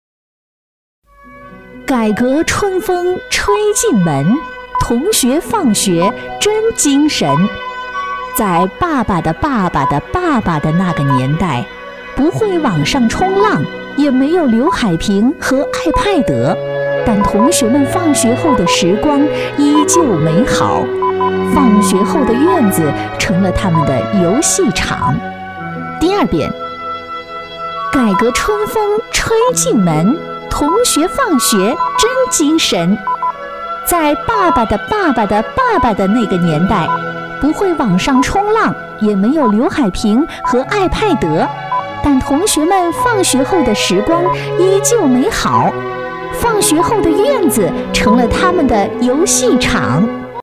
• 女S114 国语 女声 新闻 7080年代 复古播音 亲切甜美